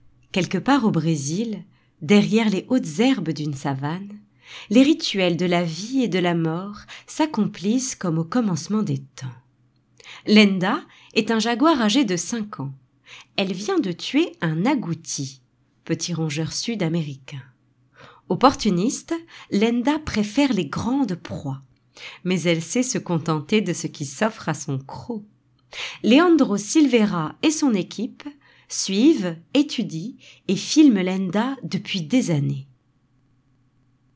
Voix off Animalier
17 - 50 ans - Soprano